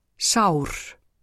uttale